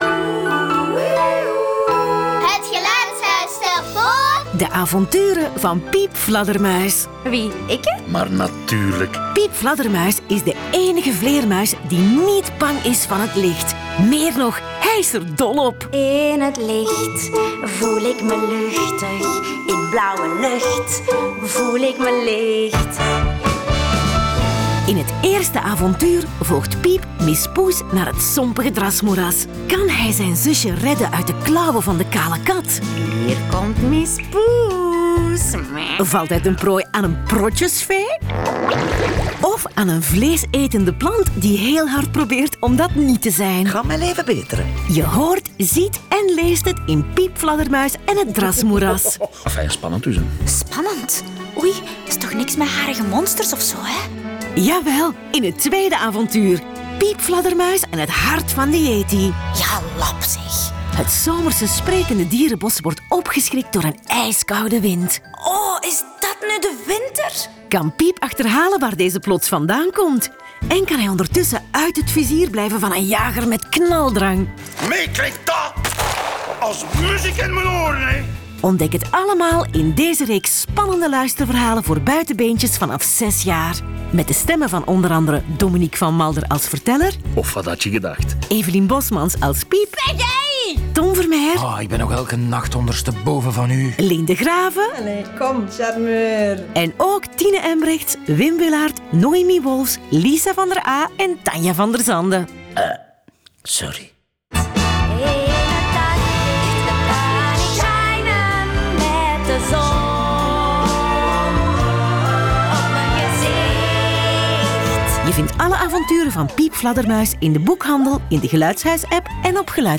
e trailer van de reeks Piep Fladdermuis.
De rollen worden ingesproken door de béste acteurs en in bijhorend prentenboek staat ook een voorleestekst.